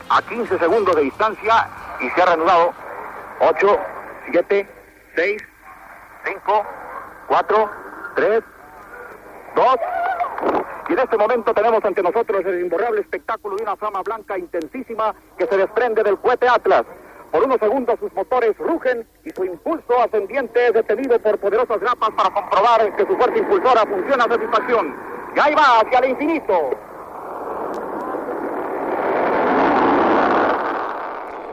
Fragment extret del programa "Audios para recordar" de Radio 5 emès el 23 de maig del 2016.